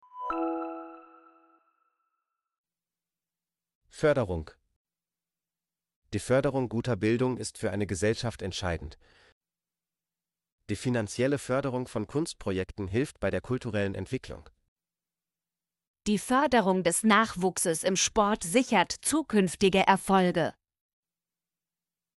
förderung - Example Sentences & Pronunciation, German Frequency List